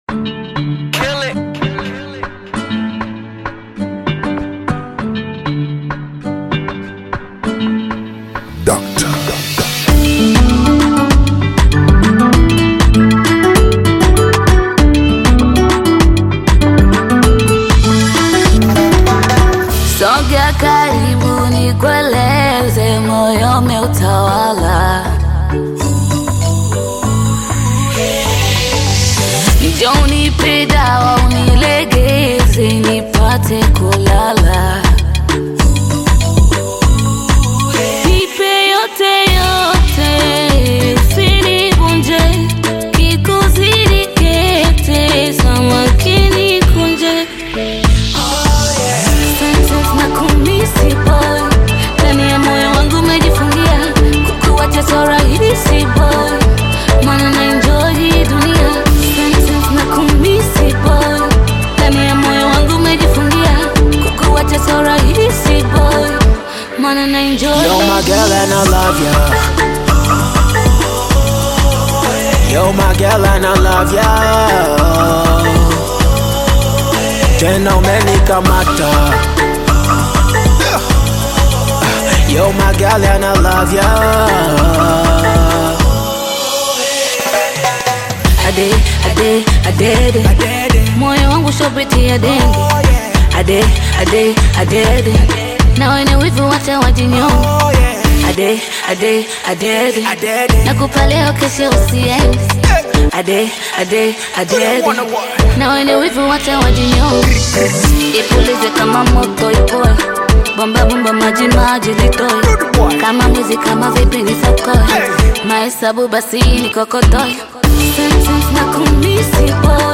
Bongo Flava artist
African Music